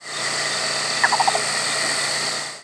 presumed Yellow-billed Cuckoo nocturnal flight calls